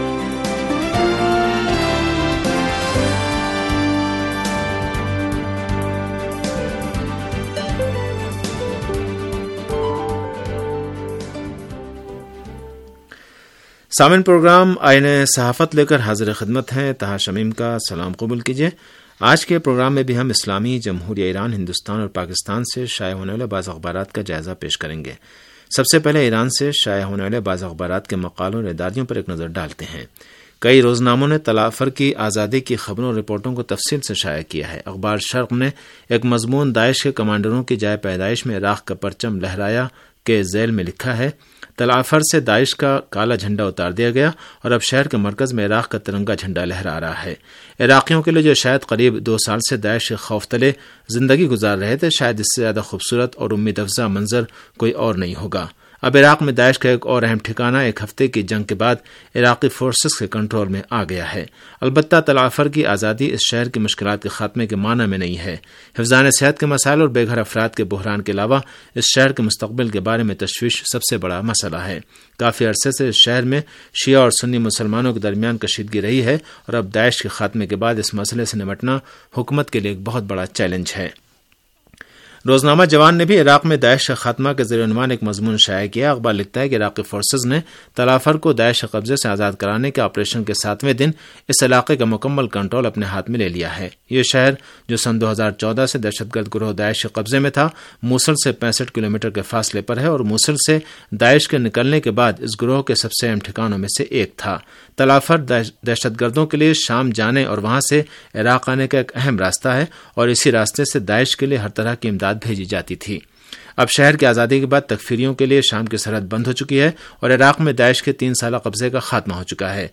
ریڈیو تہران کا اخبارات کے جائزے پر مبنی پروگرام - آئینہ صحافت